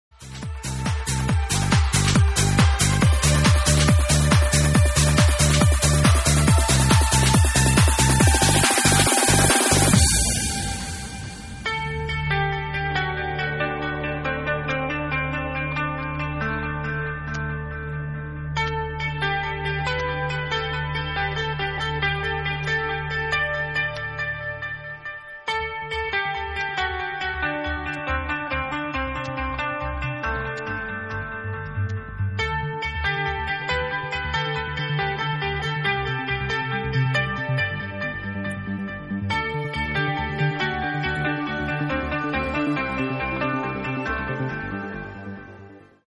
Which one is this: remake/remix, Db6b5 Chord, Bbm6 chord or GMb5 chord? remake/remix